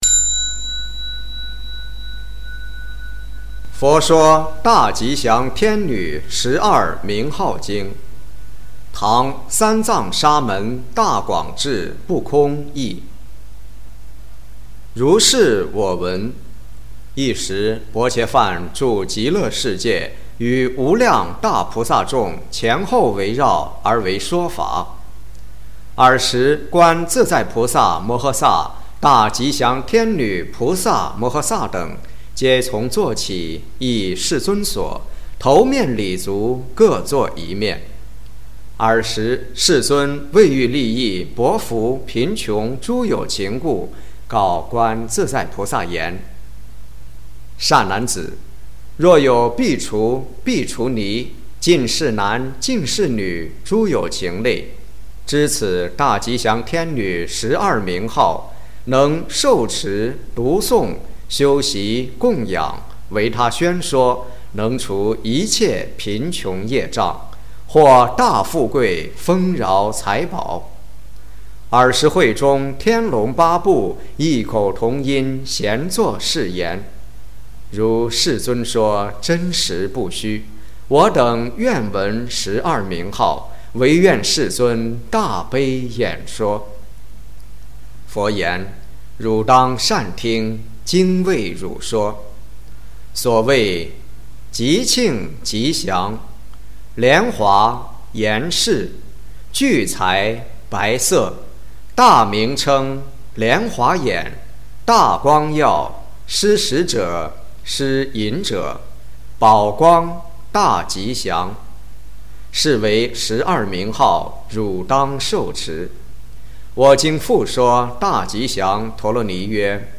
佛说大吉祥天女十二名号经 诵经 佛说大吉祥天女十二名号经--未知 点我： 标签: 佛音 诵经 佛教音乐 返回列表 上一篇： 准提咒（念诵） 下一篇： 佛说八大人觉经（念诵） 相关文章 慈佑众生观世音 慈佑众生观世音--佛教音乐...